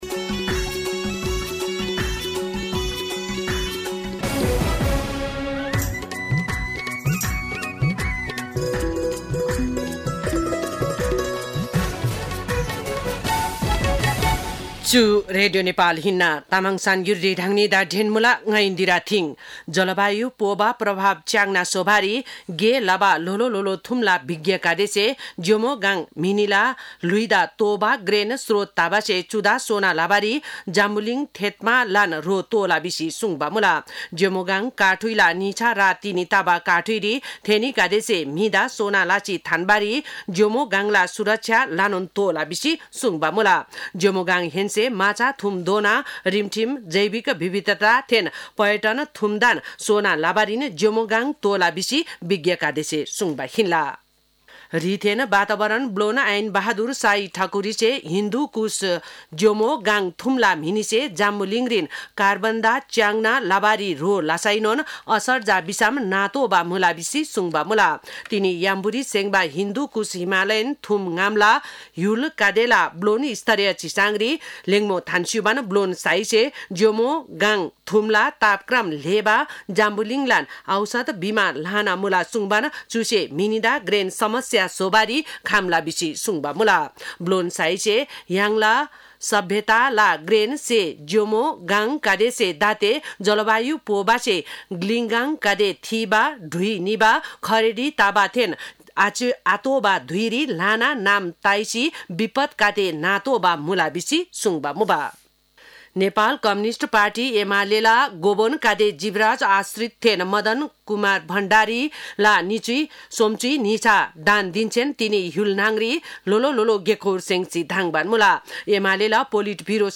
तामाङ भाषाको समाचार : ३ जेठ , २०८२
5.5-pm-tamang-news-1.mp3